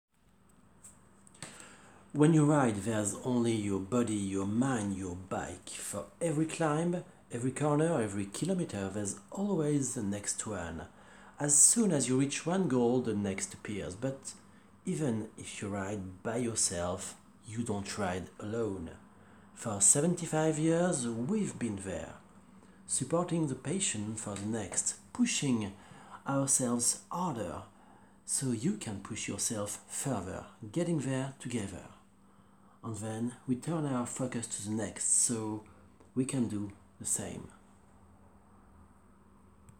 Voix off
Bande son test publicité moto, en anglais
40 - 67 ans - Basse